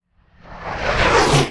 fx_explosion_artillery_02.wav